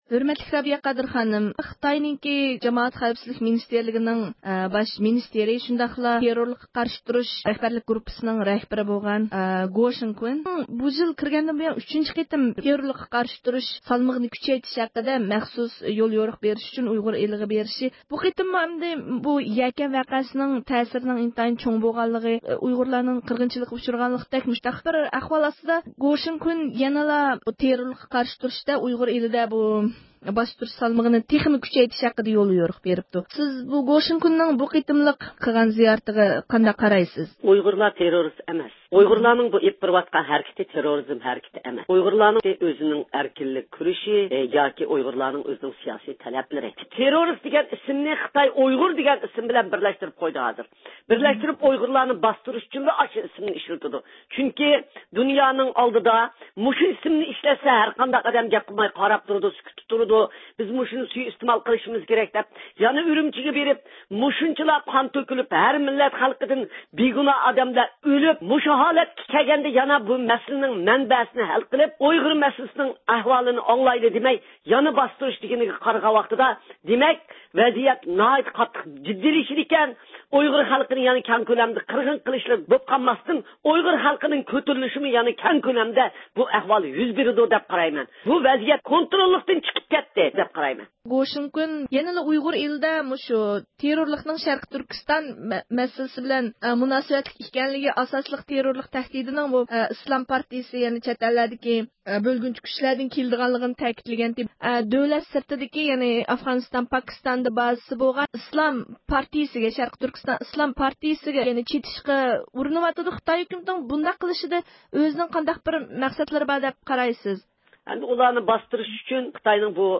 بۇ مۇناسىۋەت بىلەن رادىئويىمىزنىڭ مەخسۇس زىيارىتىنى قوبۇل قىلغان، ئۇيغۇر مىللىي ھەرىكىتى رەھبىرى رابىيە قادىر خانىم، نۆۋەتتە دۇنيا ئۇيغۇر قۇرۇلتىيىنىڭ ئەڭ ئالدىنقى خىزمەت نىشانىنىڭ بىرلەشكەن دۆلەتلەر تەشكىلاتى قاتارلىق مۇستەقىل ئورگانلارنىڭ يەكەندە خىتاينىڭ ئېلىپ بارغان قىرغىنچىلىق ھەرىكىتى ئۈستىدىن تەكشۈرۈش ئېلىپ بېرىشىنى قولغا كەلتۈرۈش ئىكەنلىكىنى ئوتتۇرىغا قويدى.